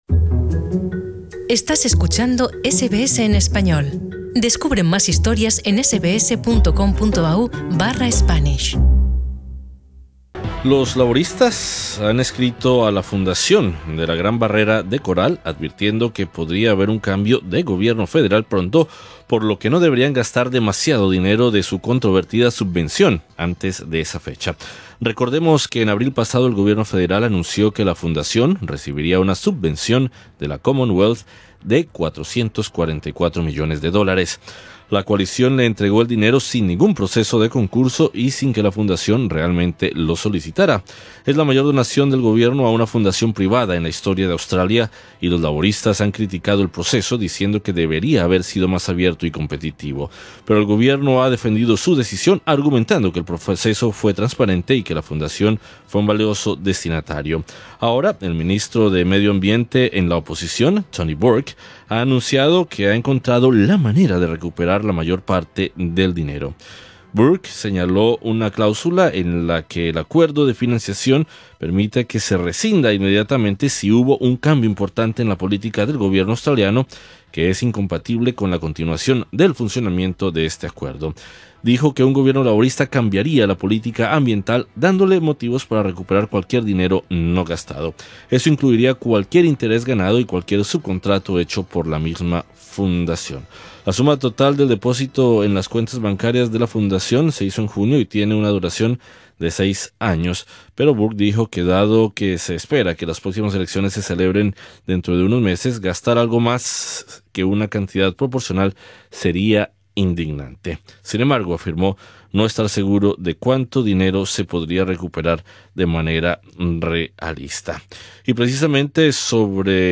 Sobre el impacto de esta advertencia conversamos con el experto e investigador de la barrera de Coral